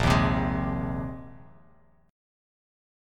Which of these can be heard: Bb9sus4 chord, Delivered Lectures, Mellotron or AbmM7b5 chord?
Bb9sus4 chord